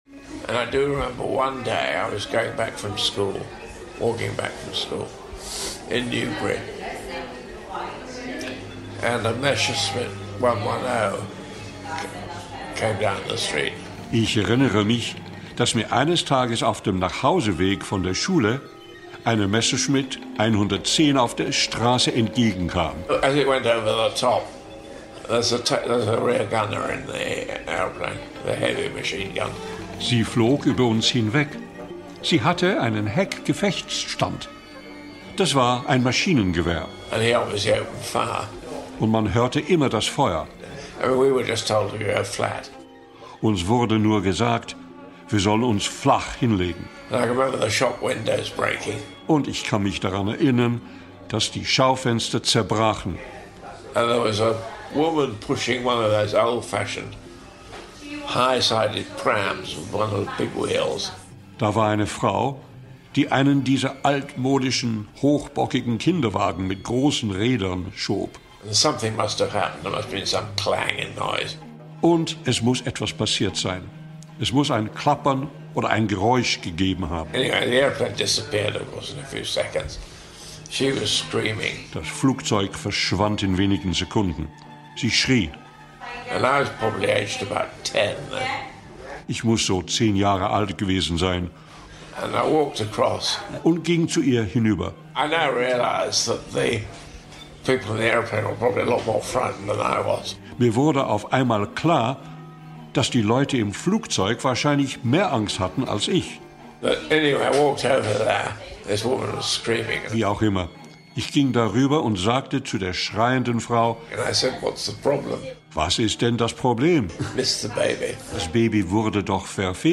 Unter besonderer Berücksichtigung eines fächerverbindenden Ansatzes im Schnittfeld von Geschichts- und Deutschunterricht untersuchen die Schülerinnen und Schüler ein Hörspiel.